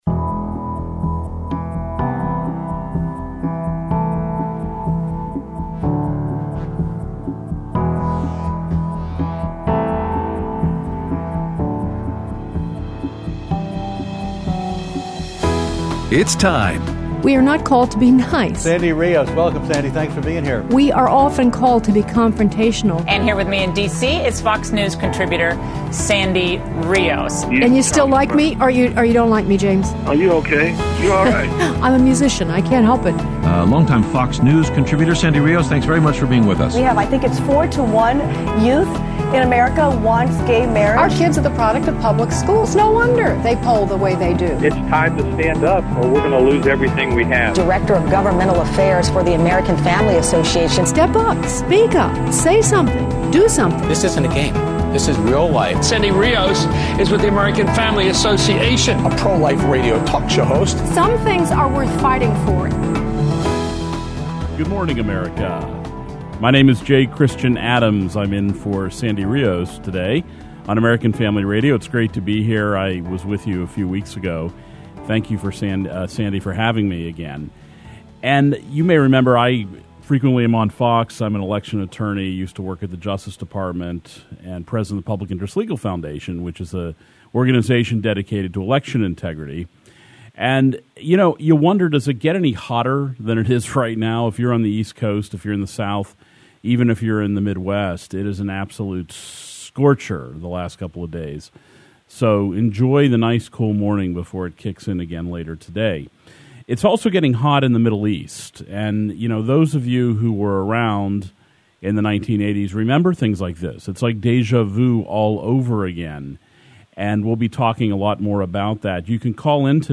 Aired Monday 7/22/19 on AFR 7:05AM - 8:00AM CST